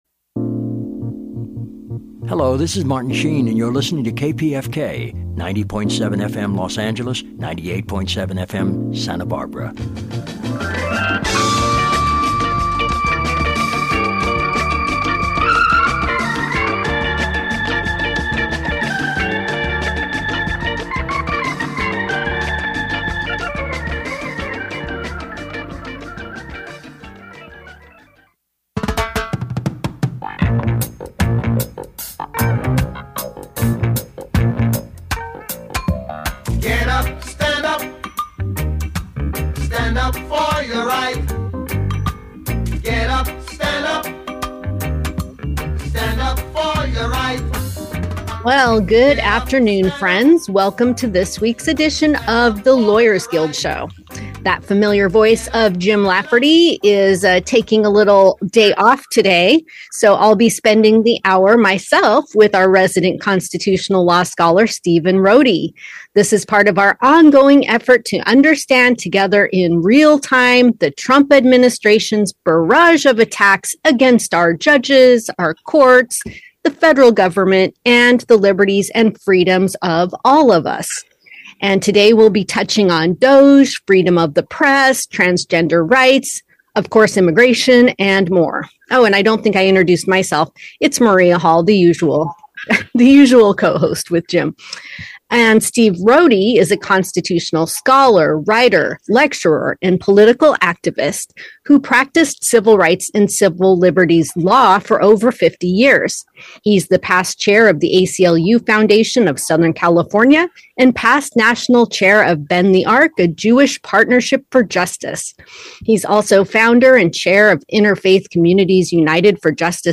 A public affairs program where political activists and experts discuss current political developments and progressive movements for social change.